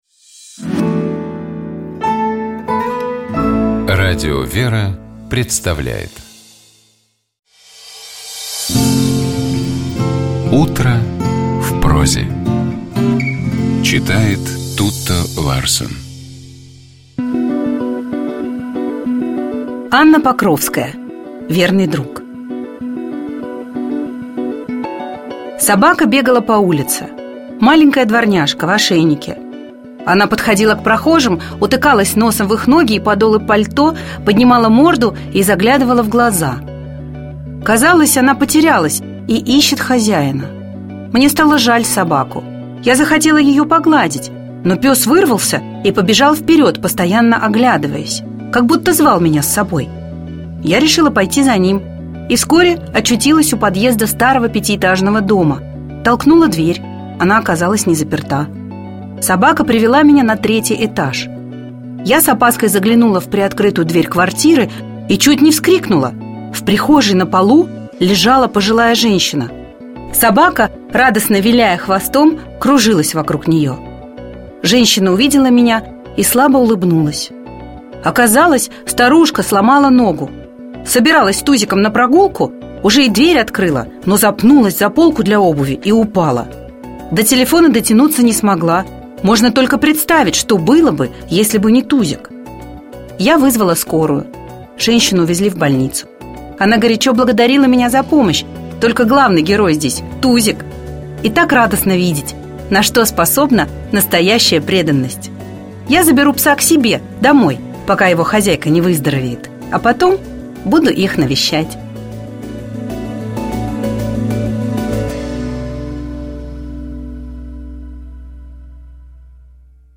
Текст Анны Покровской читает Тутта Ларсен.